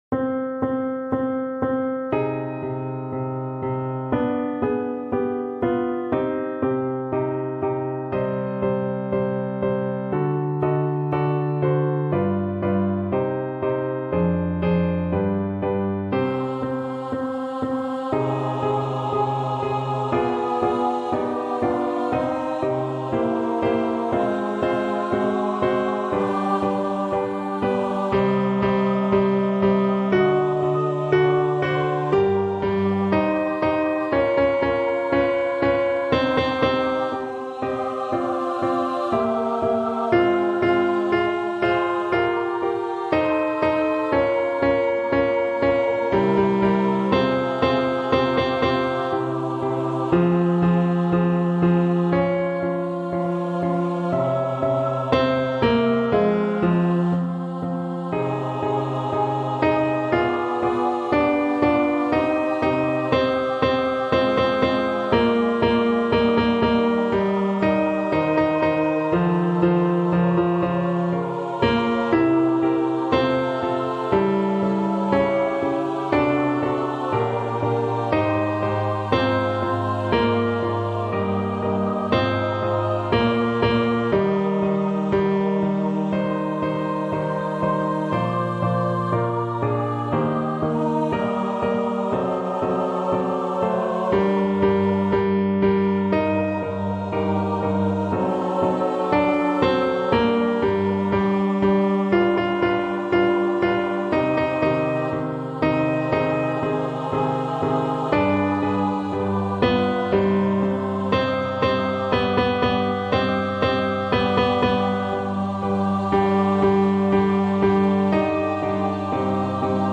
Ténors